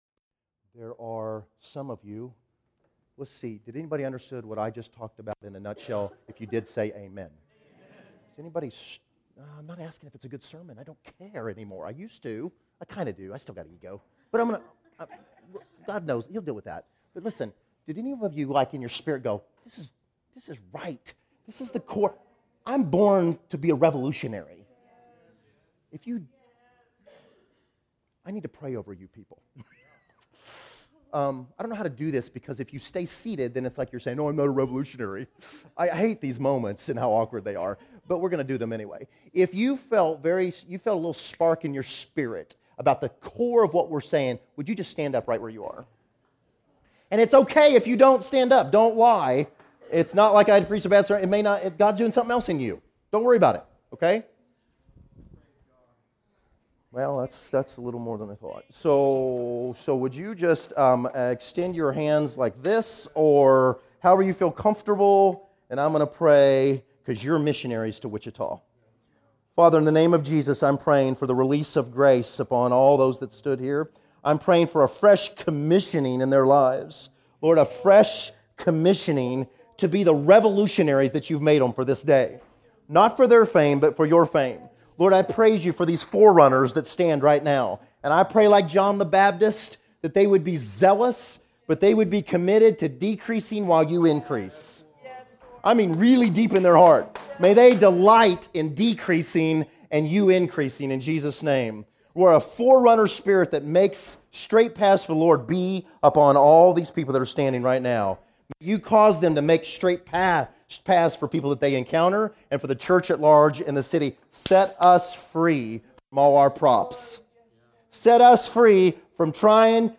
Category: Encouragements